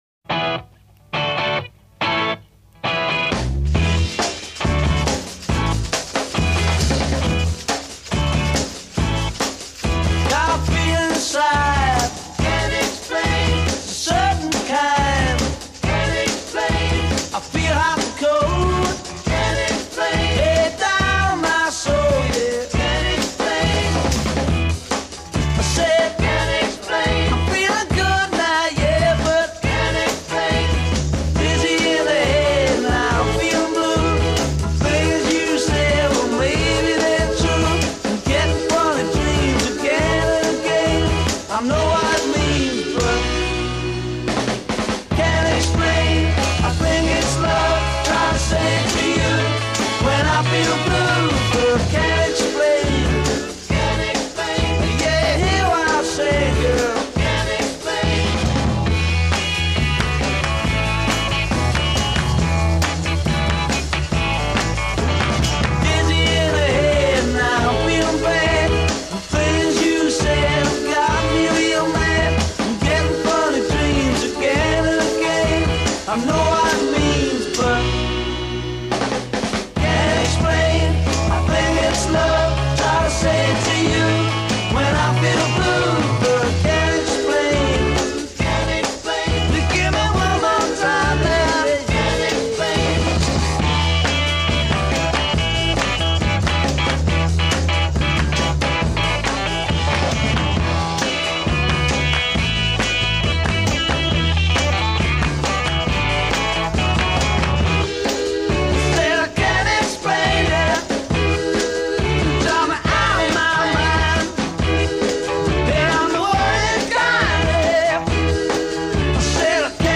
bass guitar
drums
backing vocals
Recorded: IBC Studios, London, November 1964, November 1964.
A Verse A 0: 16 double-tracked lead vocal;
chorus responds with hook at the end of each line   a
A Verse B : 16 Chorus sustains harmonies under lead vocal. c
B Bridge : 8 Two-part harmony (singer with self?) d
Refrain : 8 Guitar solo.